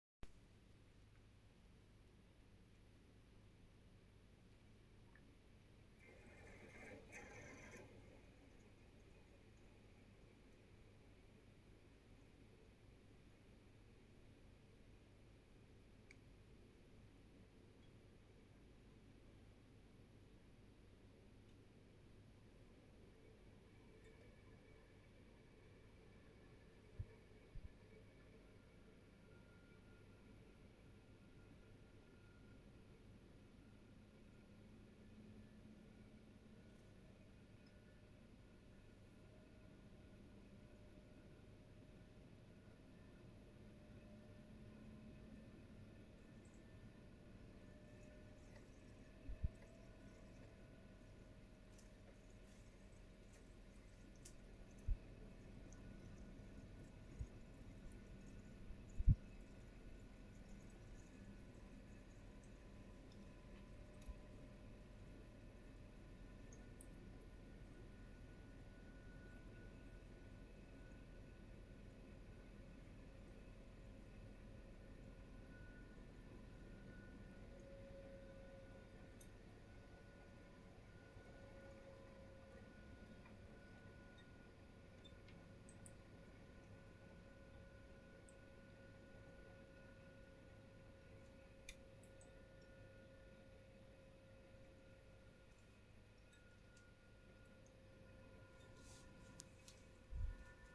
La clim fait du bruit à peu près toute les 5 minutes pendant 3 minutes...
On entend presque pas de soufflerie, mais voilà que toute les 5 minutes, elle va faire un bruit comme de purge, puis souffler très fort quelques instants, faire un ultrason très désagréable (que mon mari n'a pas le plaisir d'entendre) de temps en temps quelques "gling" et après goûte d'eau à l'intérieur (très léger qu'on entend pas dans mes enregistrements...)
bruit-climatisation-1-1.mp3